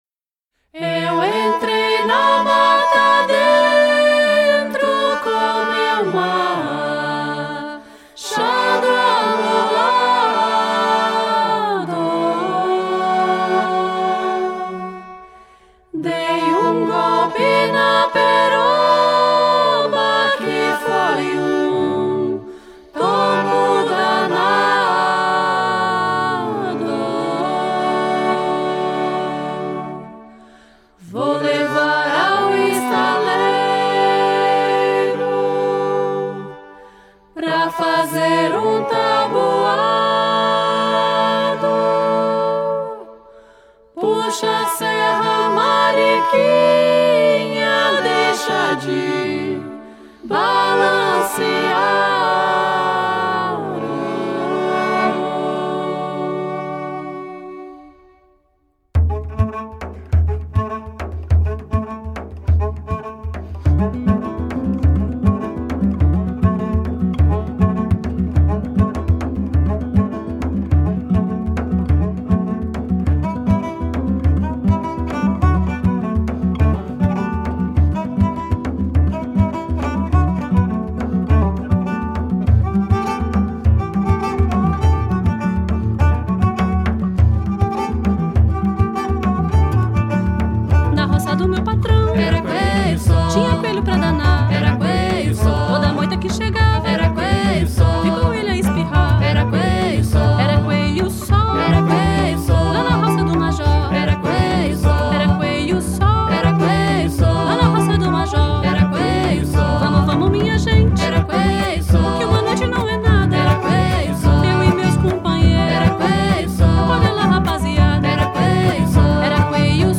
＊試聴は01→04→09です。